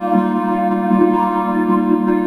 VOCODE LP -L.wav